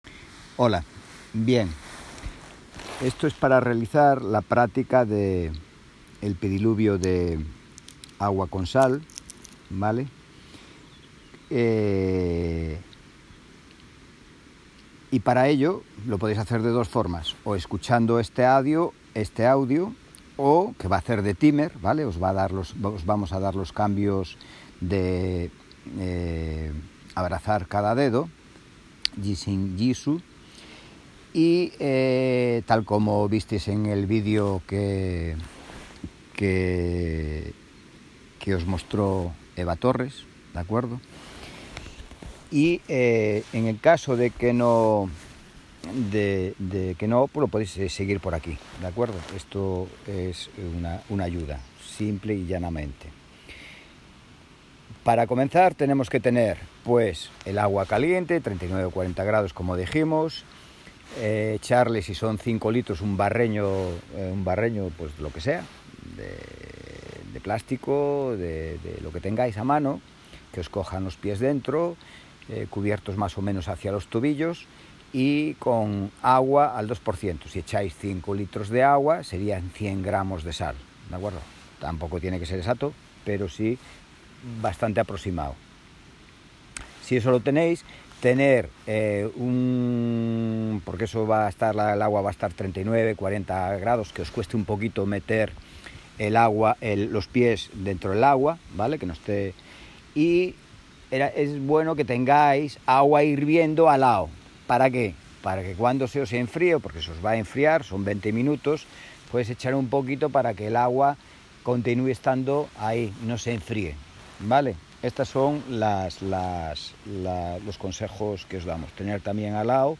AUDIO DE LA PRÁCTICA CON TIEMPOS